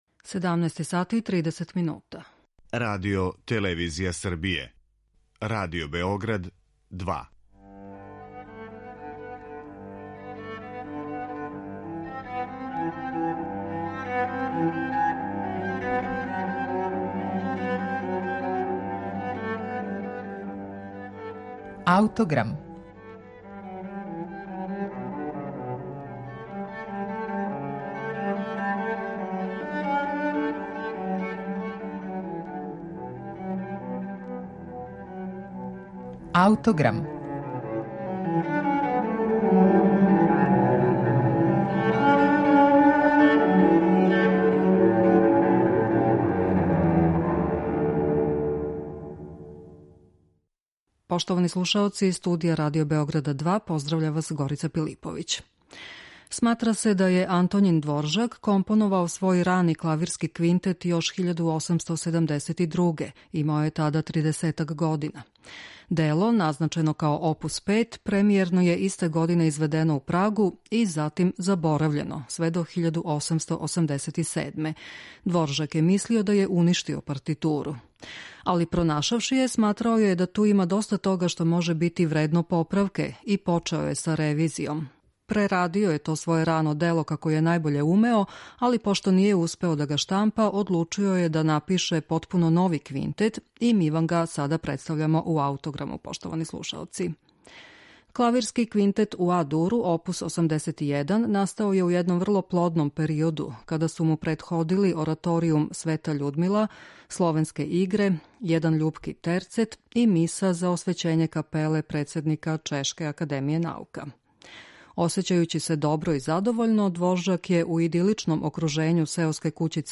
Други клавирски квинтет Антоњина Дворжака